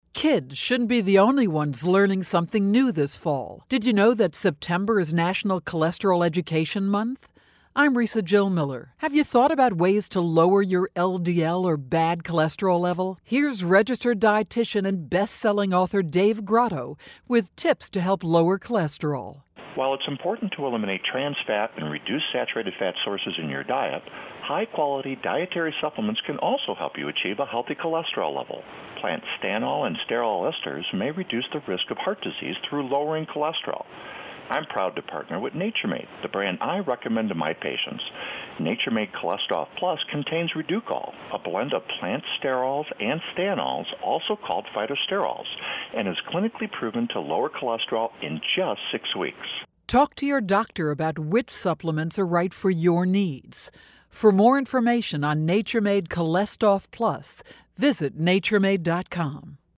September 24, 2013Posted in: Audio News Release